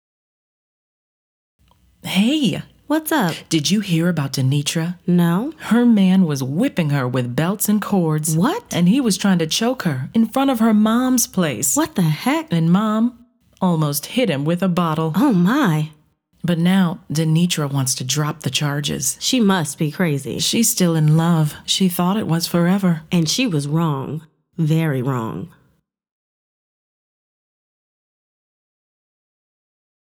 FA103_AllNew_NextMonday30_sot.wav